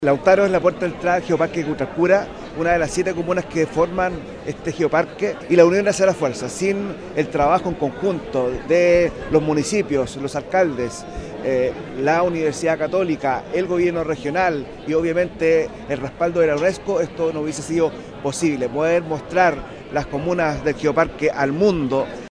Con delegaciones de más de 50 países se inauguró en el Teatro Municipal de Temuco, región de La Araucanía, la undécima conferencia internacional de Geoparques de la Unesco, que se realiza por primera vez en Sudamérica.
En ese sentido, el alcalde de Lautaro, Ricardo Jaramillo, dijo que sin un trabajo en conjunto no se podría haber realizado la conferencia.